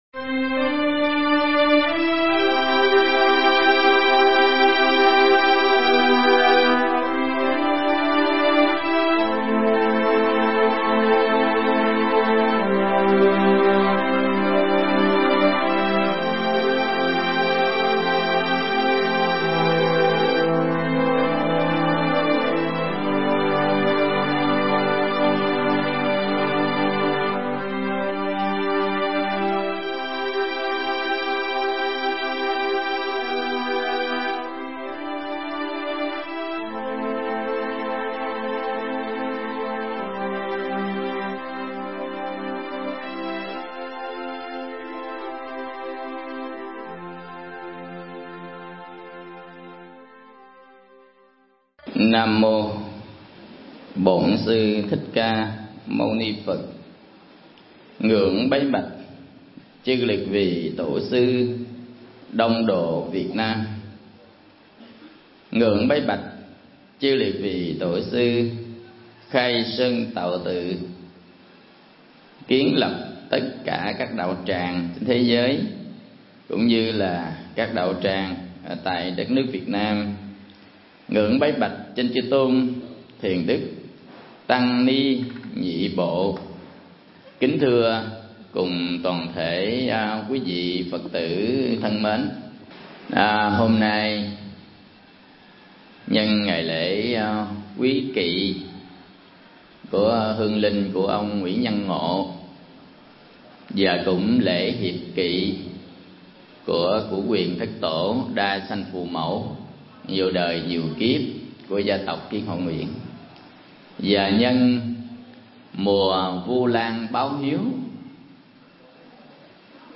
Mp3 Pháp Thoại Thế nào là giáo pháp nhà Phật?
giảng tại Từ Đường Nguyễn Văn Ngộ